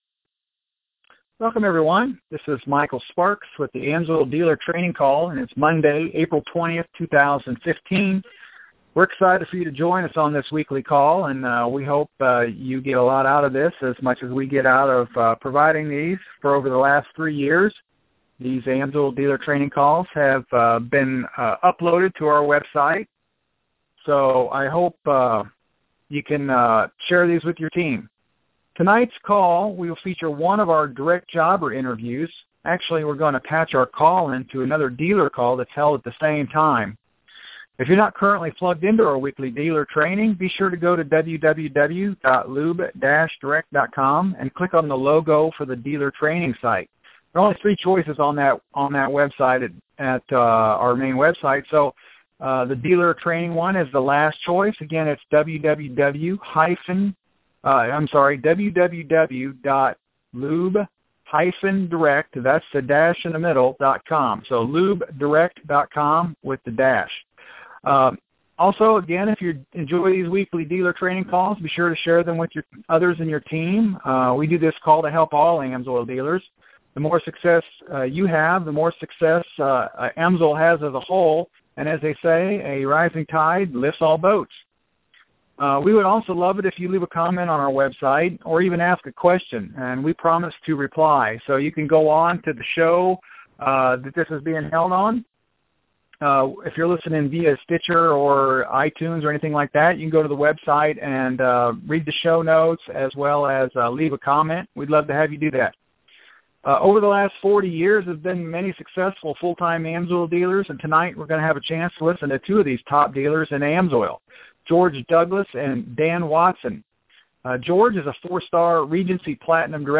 Training Call